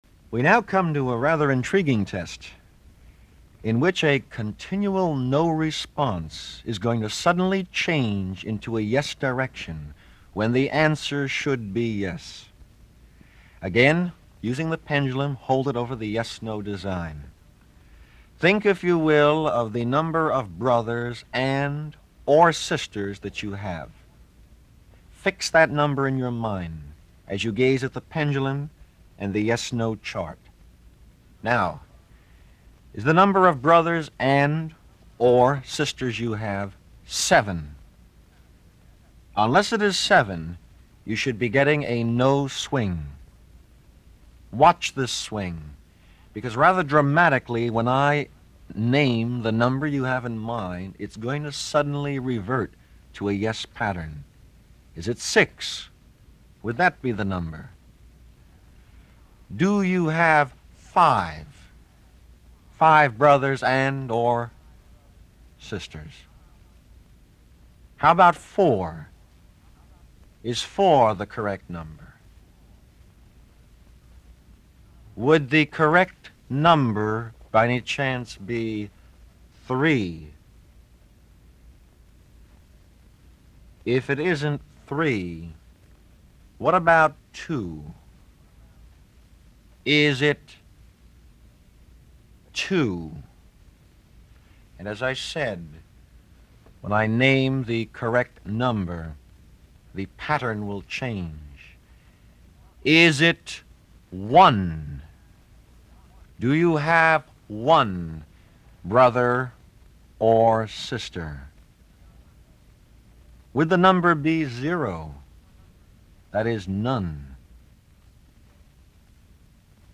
Technical Notes: This is a digital transfer (from MiniDisc to the hard drive.) Since the nature of this recording implements no stereo spectrum, these files have been rendered in Mono, at the highest resolution possible for an MP3 (128kbps.)